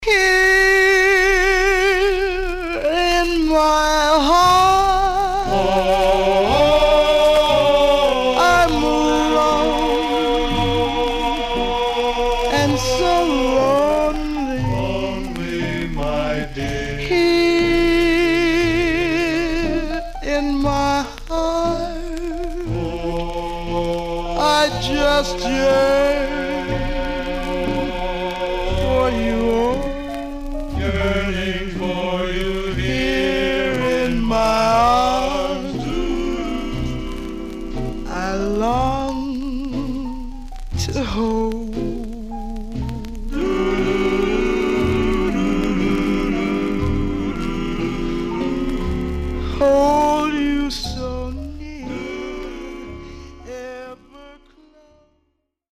Some surface noise/wear Stereo/mono Mono
Male Black Groups